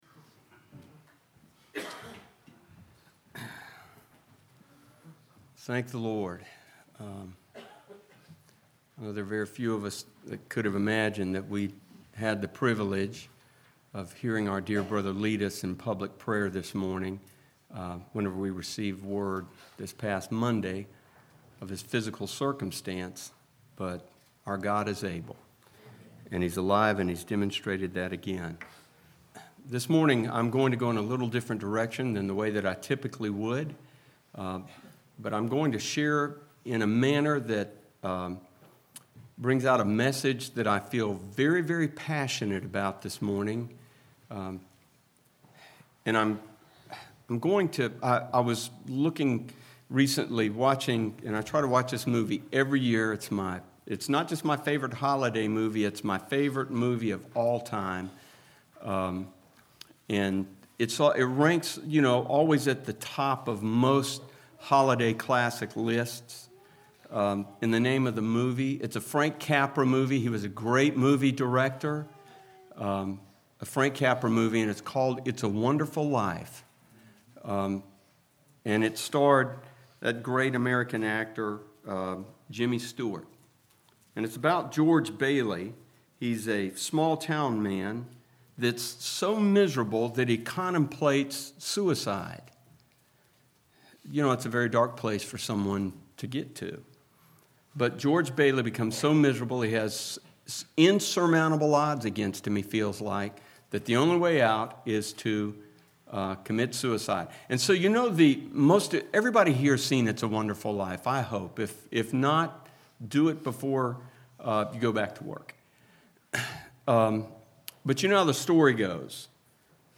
Preacher
12/25/16 Sunday Morning